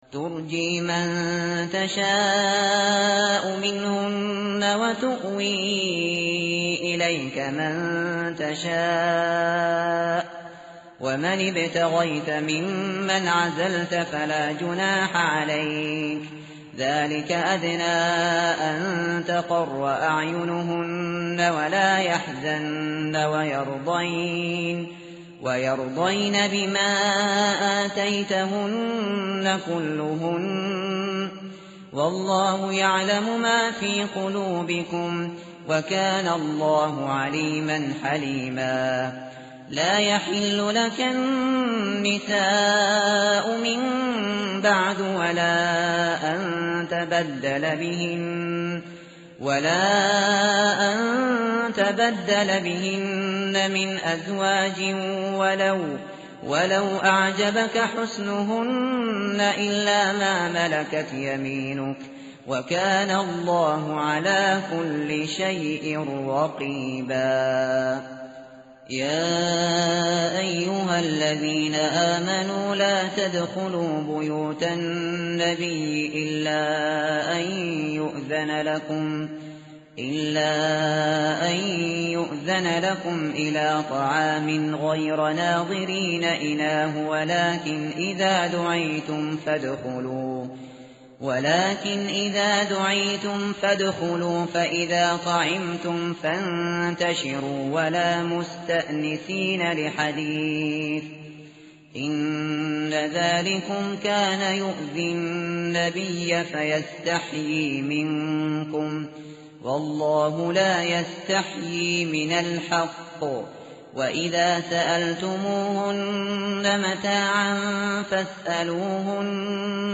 tartil_shateri_page_425.mp3